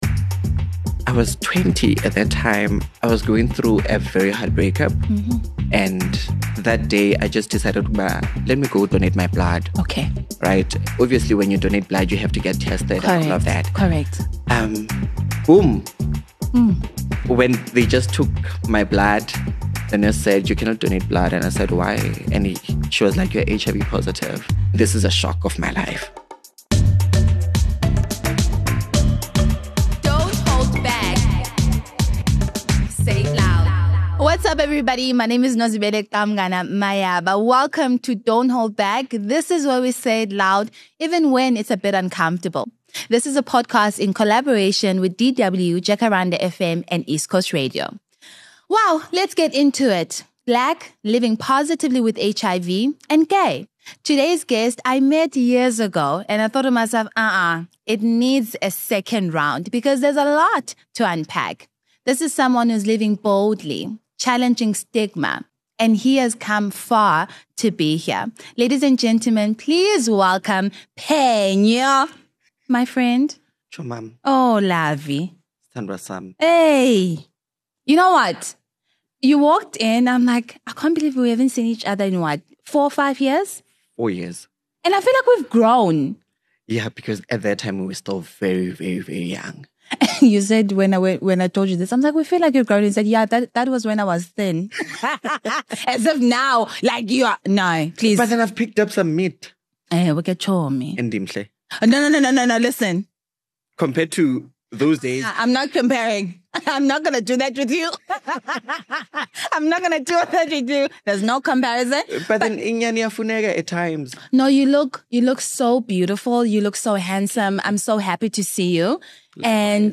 Whether you’re living with HIV, part of the queer community, or an ally ready to support without shame or saviourism, this conversation is real, raw, and full of heart.